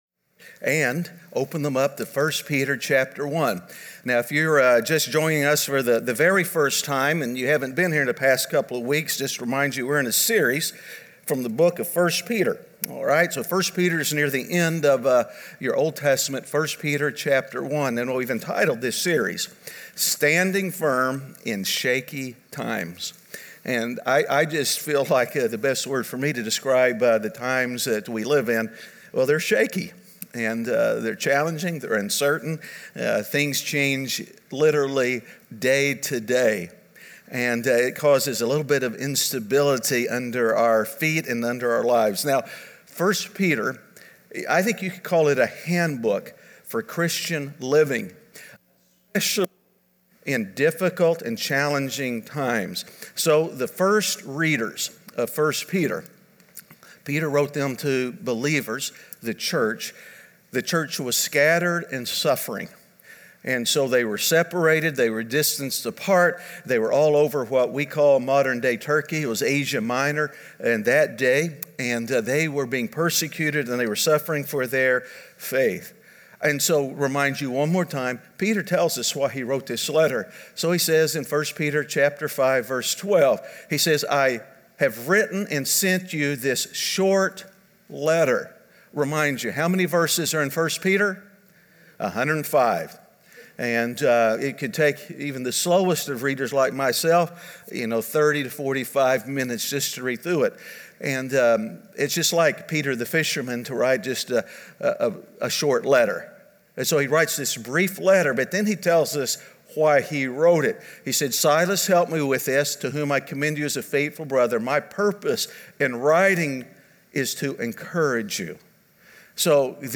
Standing Firm In Shaky Times (Week 3) - Sermon.mp3